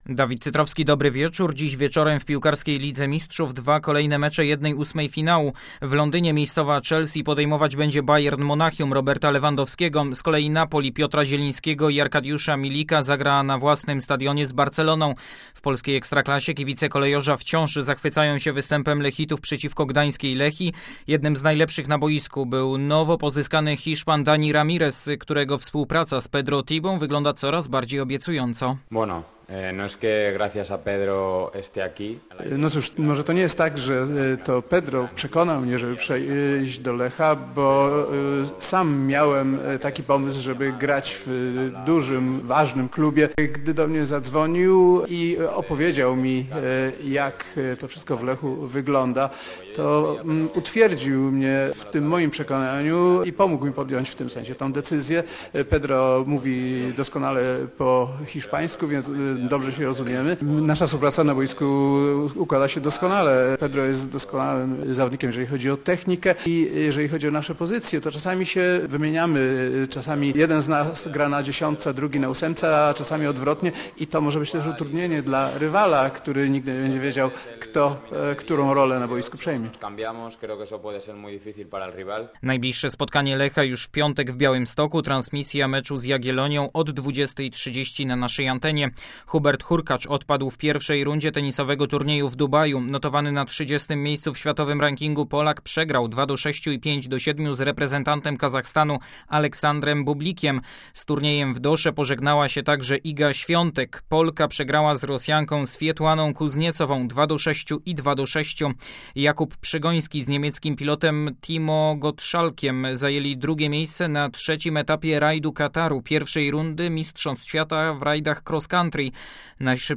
25.02. SERWIS SPORTOWY GODZ. 19:05.